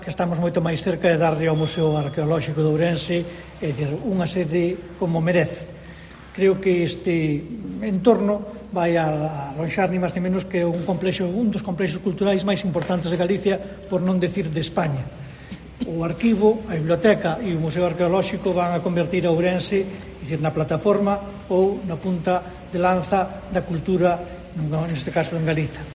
El delegado del Gobierno en Galicia, Pedro Blanco, anunció que las obras del Museo Arqueológico se encuentran en la “recta final de un largo camino” y avanzó que permitirán abrir “un nuevo espacio a la ciudadanía y a la recuperación del antiguo Pazo Episcopal”.